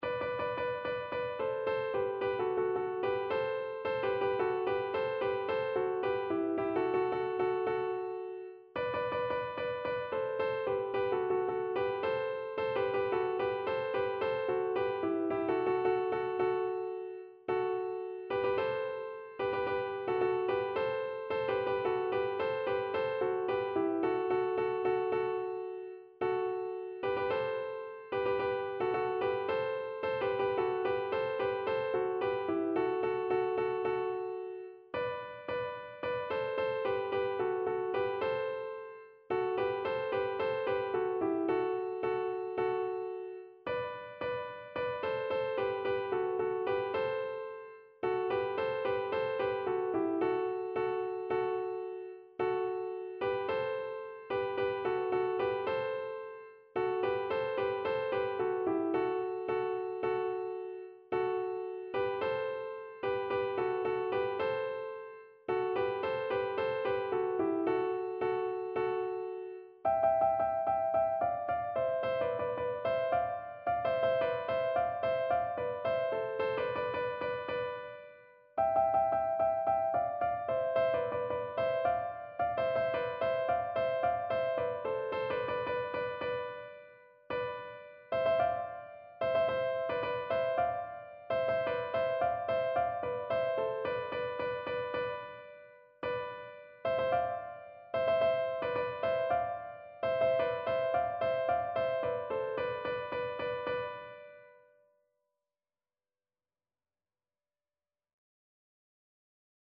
Traditional Music of unknown author.
Voice  (View more Intermediate Voice Music)
World (View more World Voice Music)